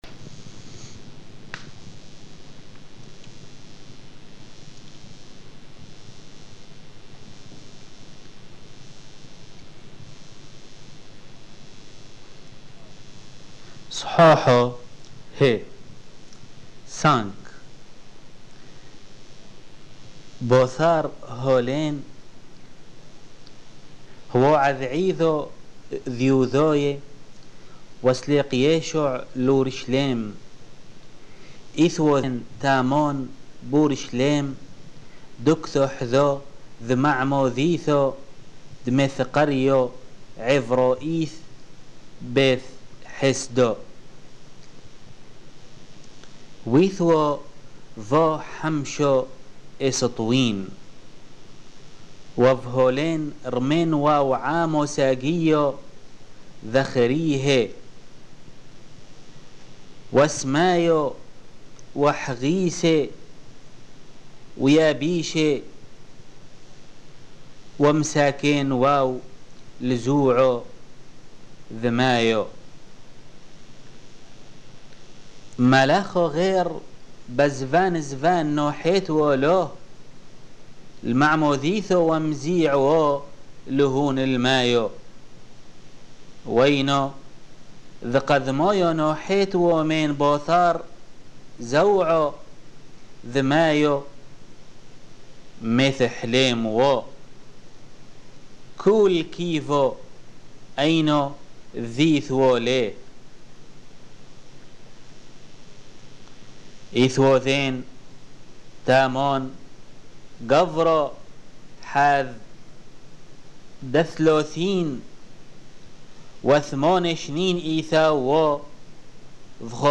Lecture de l'évangile de Jean, chap. 5-7,44 en langue syriaque (peshitta)